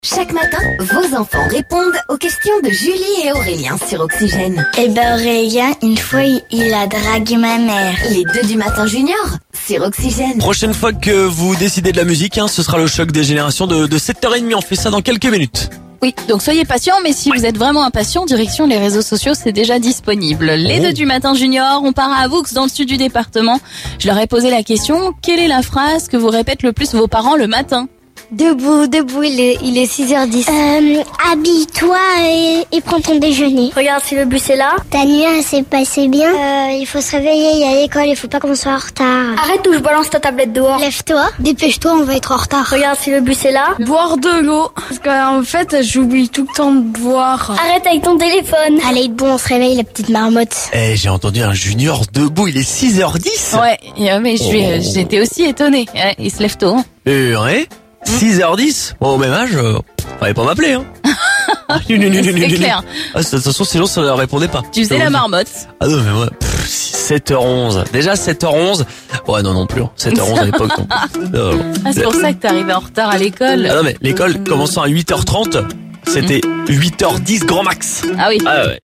Écouter le podcast Télécharger le podcast Écoutons les enfants Seine-et-Marnais nous dire quelle phrase les parents leurs disent le plus le matin...